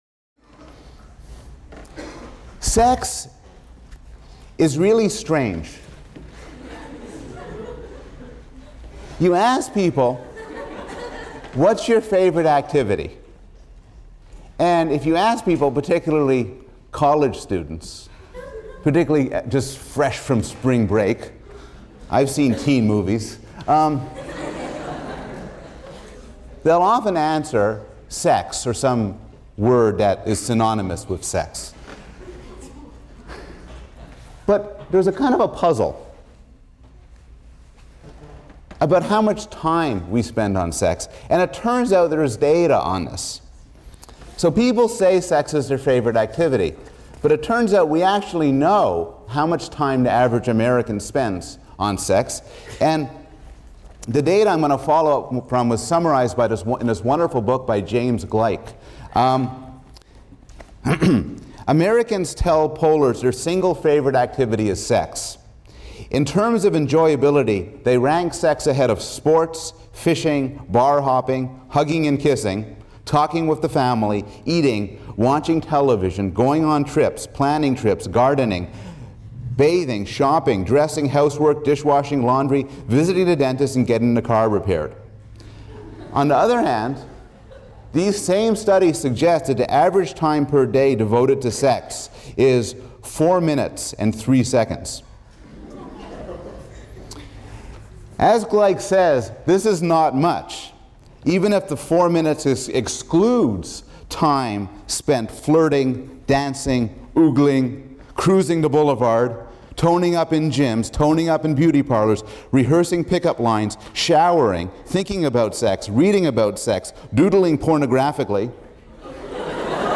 PSYC 110 - Lecture 14 - What Motivates Us: Sex | Open Yale Courses